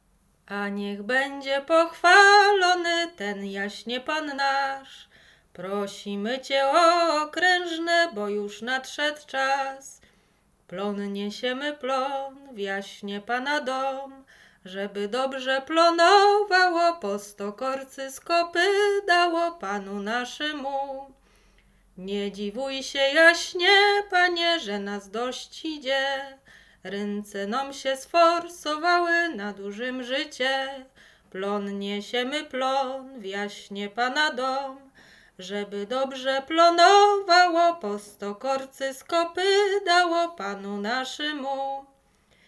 Łęczyckie
Dożynkowa